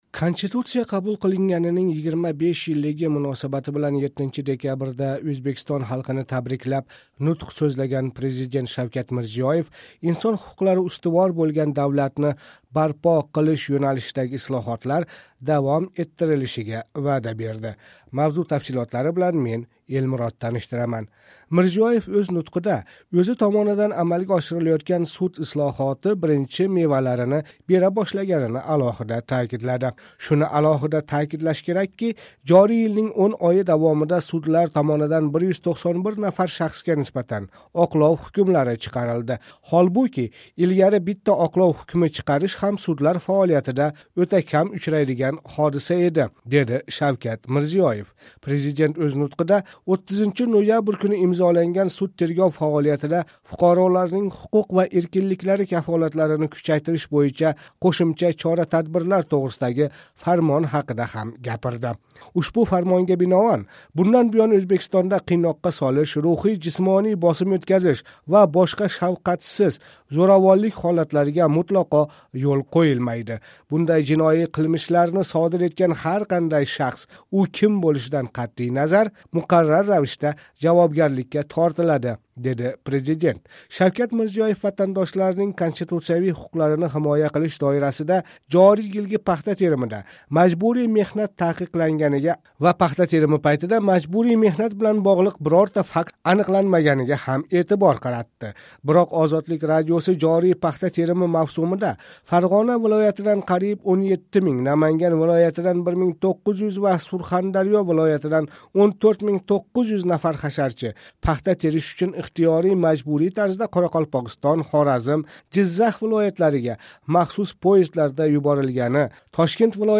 Конституция қабул қилинганининг 25 йиллиги муносабати билан 7 декабрда Ўзбекистон халқини табриклаб нутқ сўзлаган президент Шавкат Мирзиёев инсон ҳуқуқлари устивор бўлган давлатни барпо қилиш йўналишидаги ислоҳотлар давом этишини айтди.